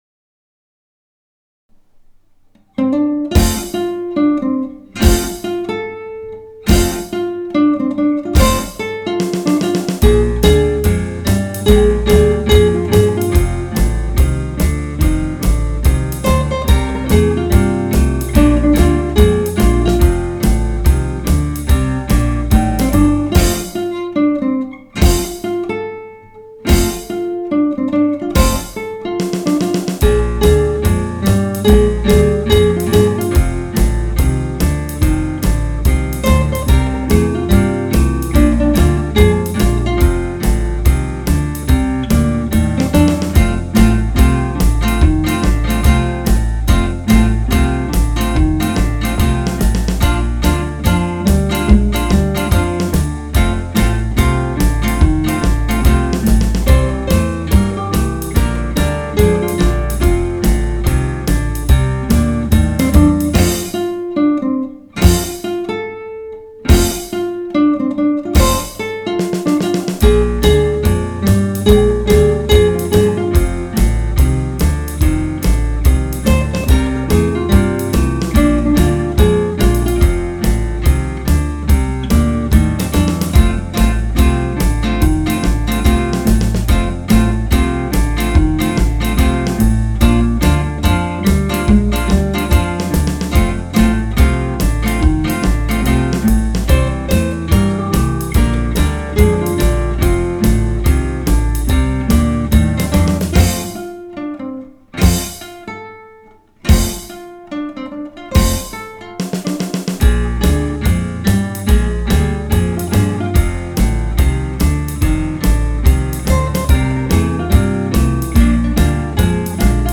Gitaar Ensemble
• 4 gitaren en bas
• Thema: Rock ’n Roll